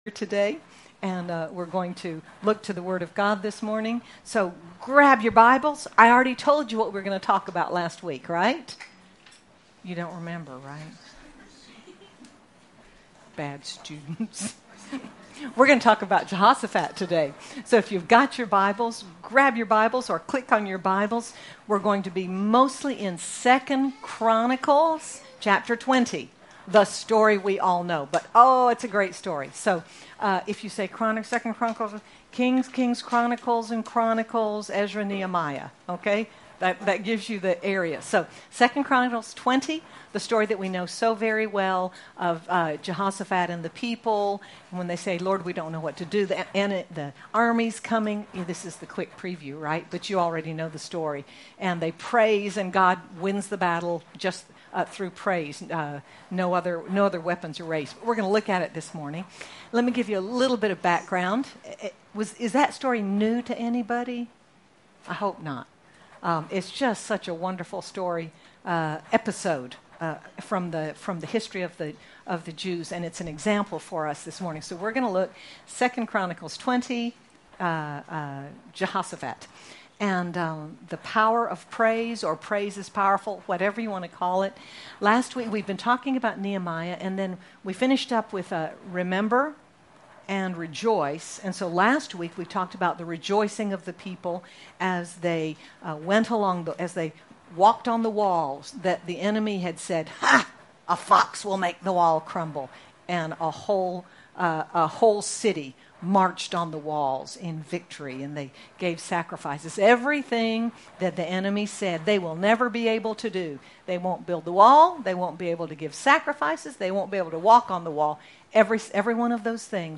The story of Jehosaphat reminds us that praise is powerful and brings a victory! Sermon by